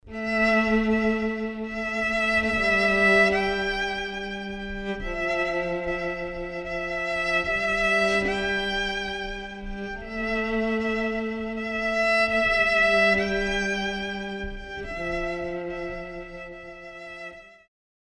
Звук грустной скрипки у нас есть отдельный сборник с такими звуками